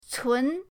cun2.mp3